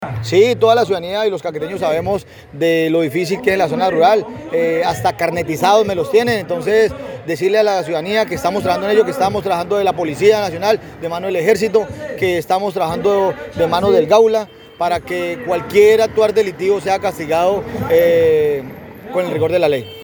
Carlos Mora, secretario de gobierno municipal, explicó que el tema preocupa a las autoridades administrativas y fuerza pública, con quienes se avanza en la prevención de este tipo de acciones ilegales y de esta manera lograr la captura y la judicialización de quienes cometan estas acciones ilegales.